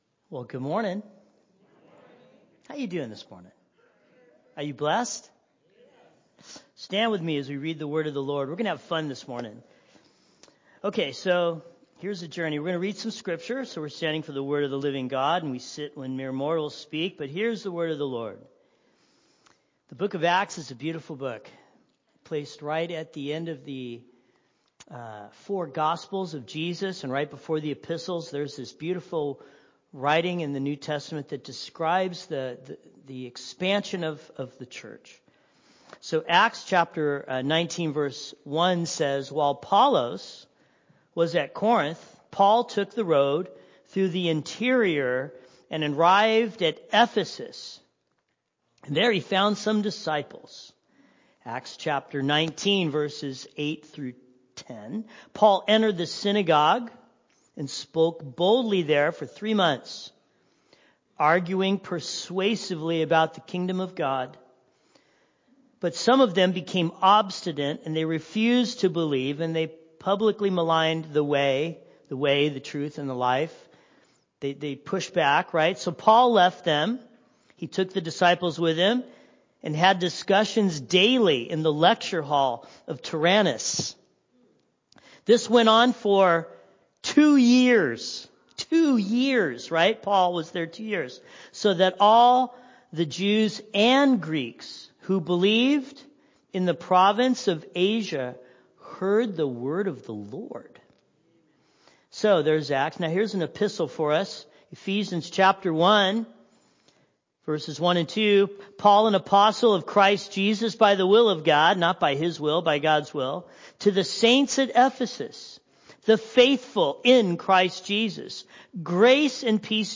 Sermon Notes: I. From enmity to unity.